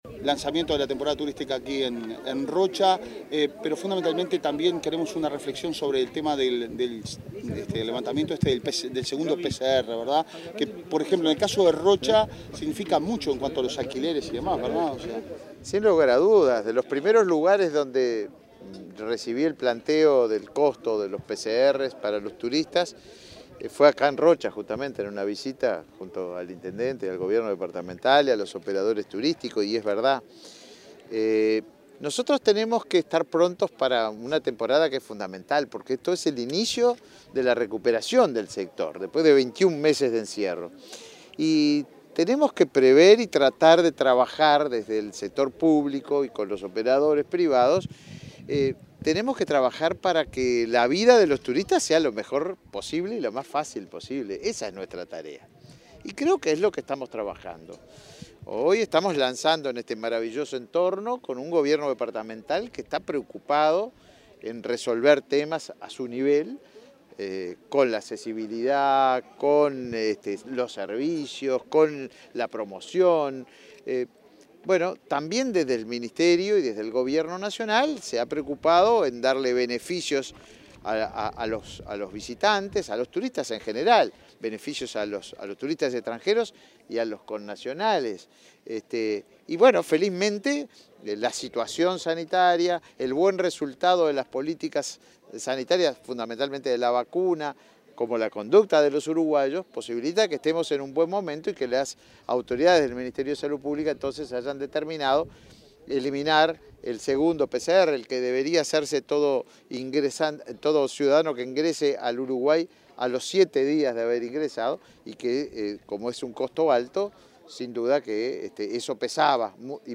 Declaraciones a la prensa del ministro de Turismo, Tabaré Viera
En esta oportunidad se presentaron beneficios sanitarios para visitantes. Tras el evento, el ministro Tabaré Viera efectuó declaraciones a la prensa.